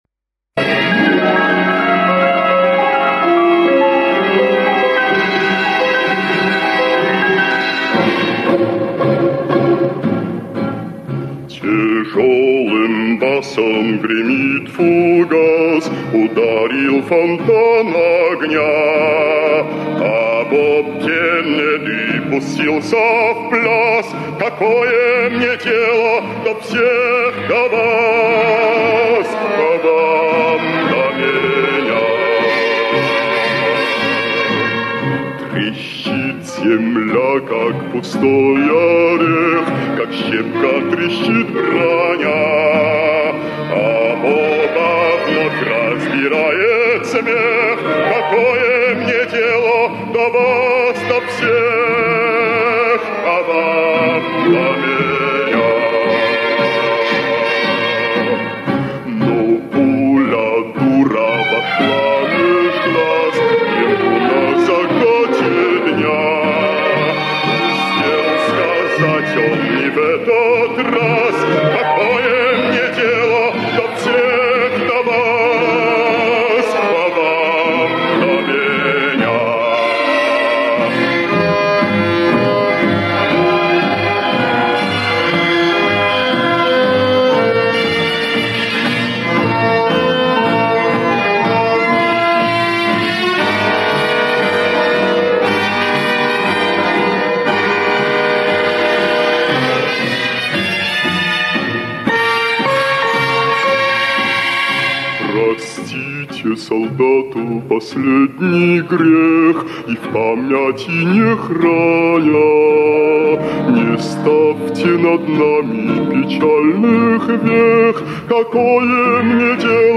редким певческим голосом - бассо-профундо
Бас-профундо это самый низкий мужской голос.